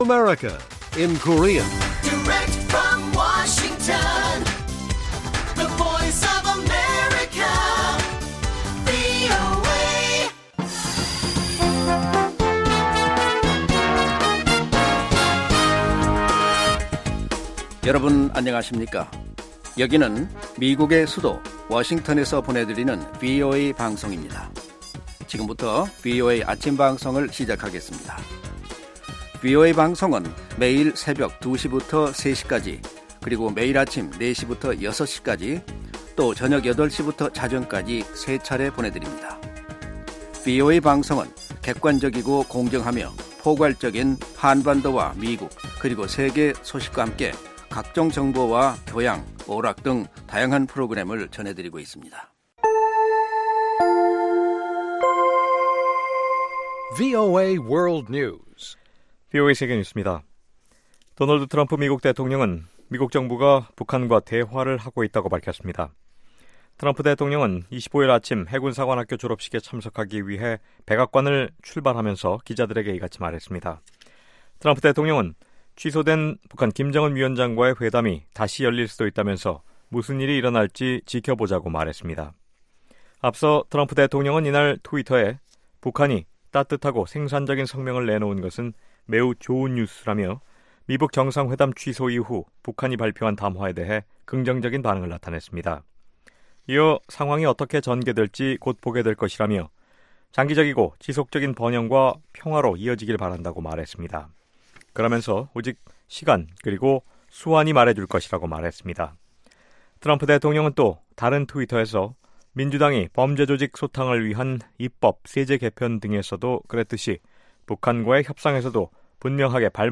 생방송 여기는 워싱턴입니다 5/26 아침
세계 뉴스와 함께 미국의 모든 것을 소개하는 '생방송 여기는 워싱턴입니다', 5월 26일 아침 방송입니다.